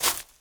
decorative-grass-05.ogg